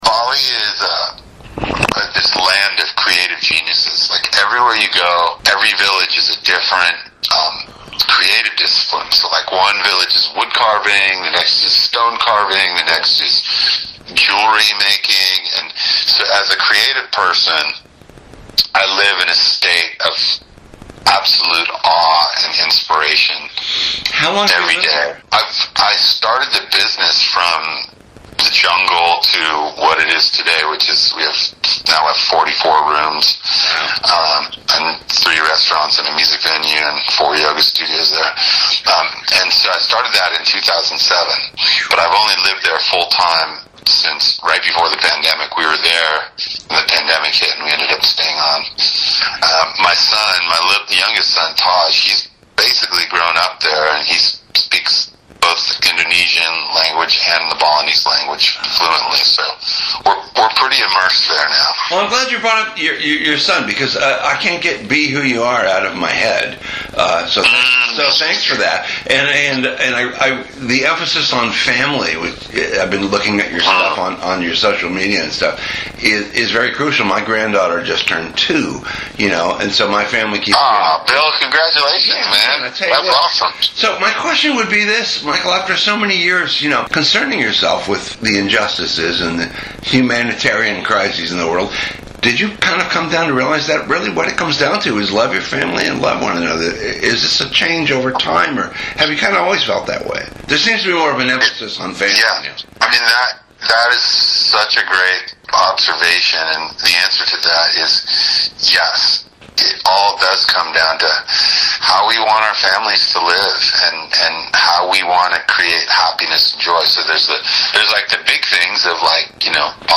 That leaves us with this Arts Alive! interview, conducted with Franti at the end of last week (he mentions the impending blessed event.) In it, he discusses all the things that motivate and inspire him.